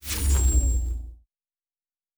Shield Device 5 Stop.wav